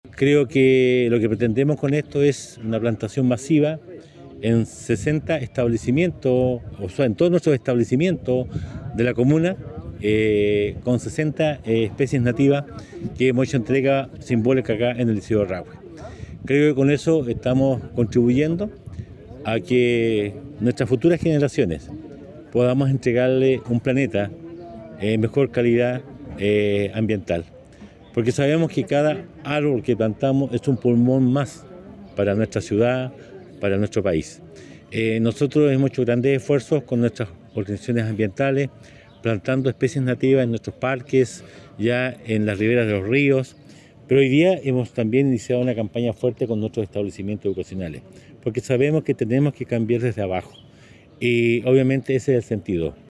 Esta plantación masiva busca contribuir a la educación medioambiental, para que las futuras generaciones tengan un mundo mucho más amigable, porque es importante comenzar desde los niños para realizar los cambios, enfatizó el jefe comunal.